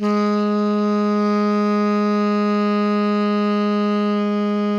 TENOR PP-G#3.wav